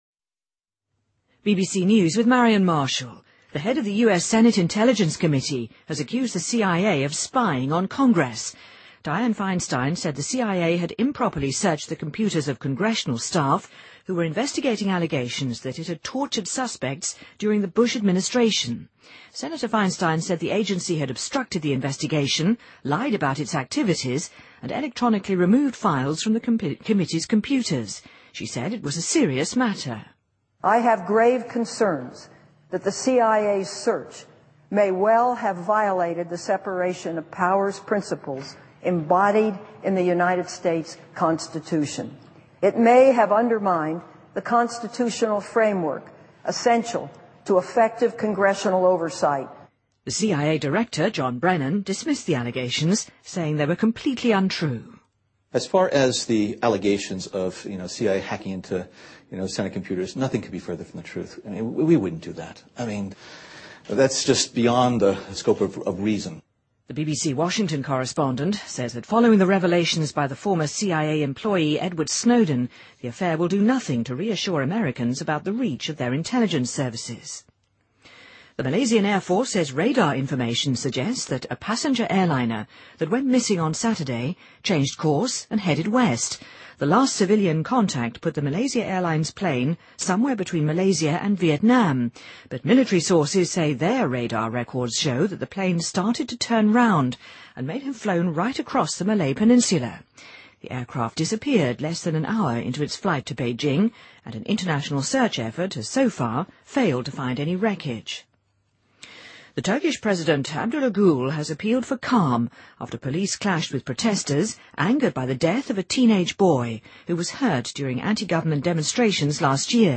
BBC news,2014-03-12